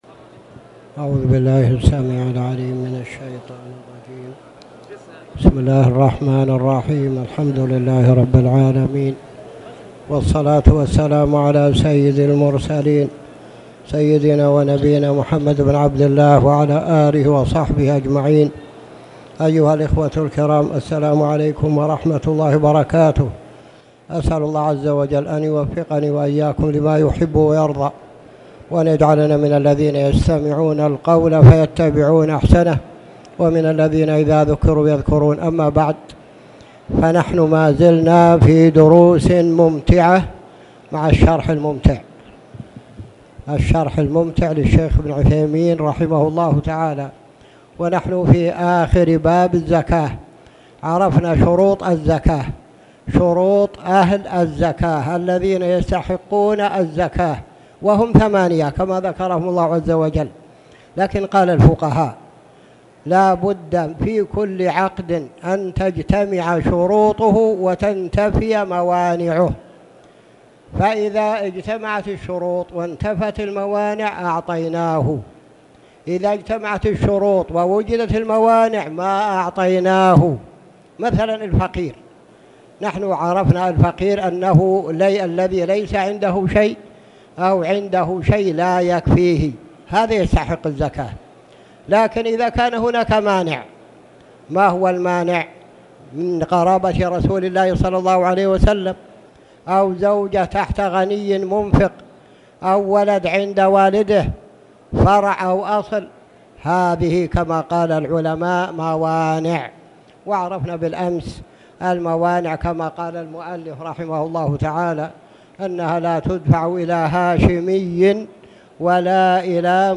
تاريخ النشر ١٤ رجب ١٤٣٨ هـ المكان: المسجد الحرام الشيخ